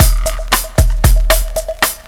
Bossa Back 09.WAV